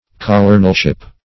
colonelship - definition of colonelship - synonyms, pronunciation, spelling from Free Dictionary
Colonelship \Colo"nel*ship\